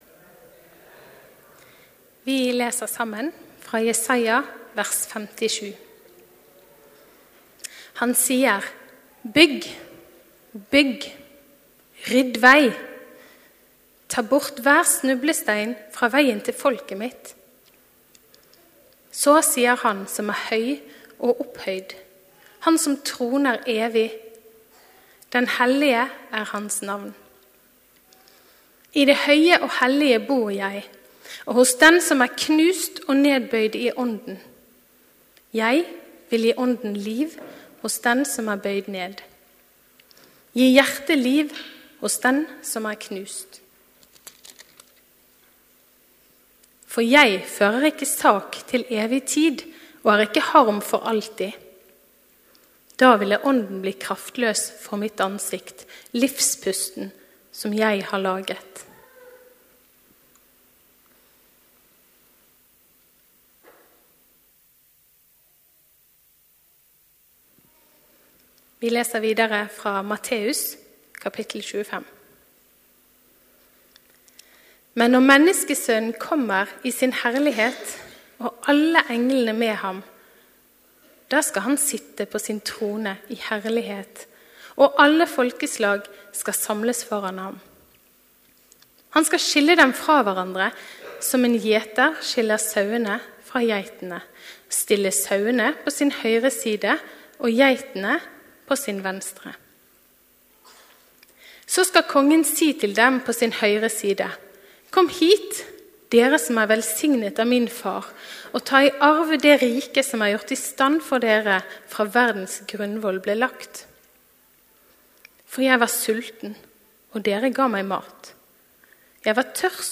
Gudstjeneste morgen 26. november, Immanuel - domssøndag | Storsalen